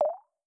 GenericNotification11.wav